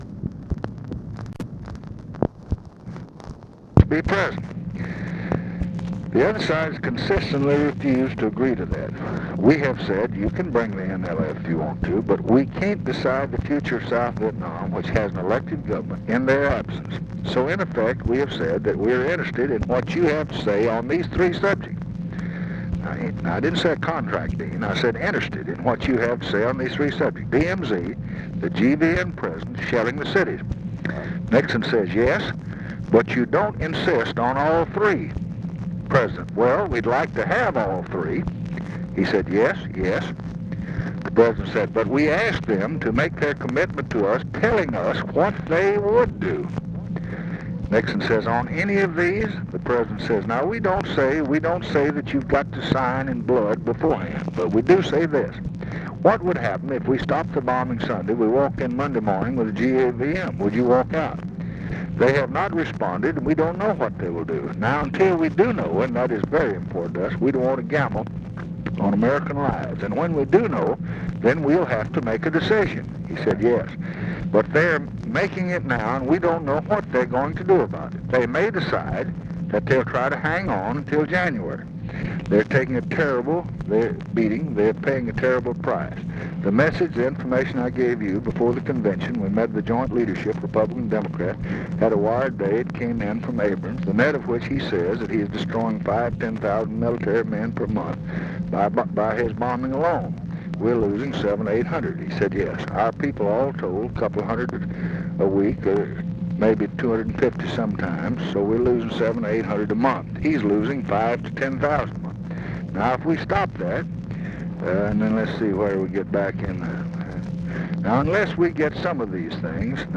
Conversation with DEAN RUSK, October 15, 1968
Secret White House Tapes